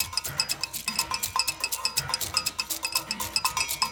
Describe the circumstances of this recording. Also below in a faux Ableton-style interface are all the original loops used to create the tracks.